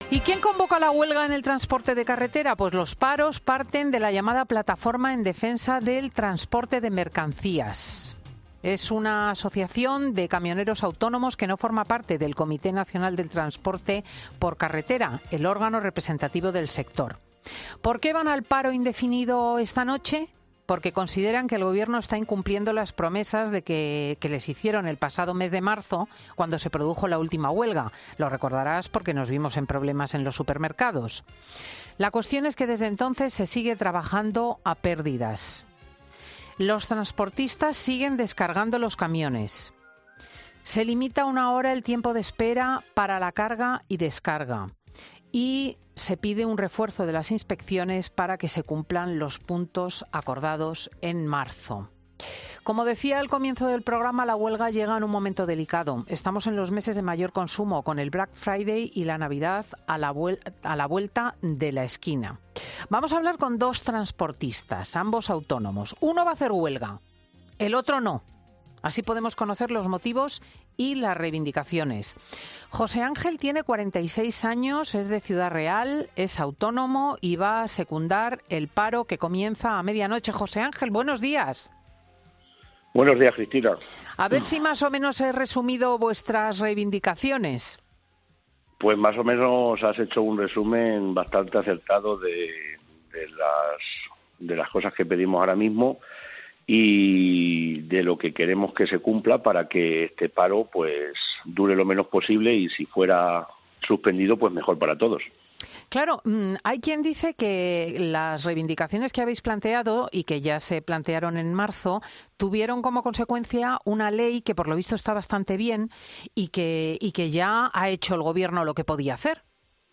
Cristina López Schlichting habla con dos transportistas con visiones contrapuestas sobre una huelga que comienza en la medianoche de este domingo